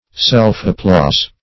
self-applause - definition of self-applause - synonyms, pronunciation, spelling from Free Dictionary
Self-applause \Self`-ap*plause"\, n.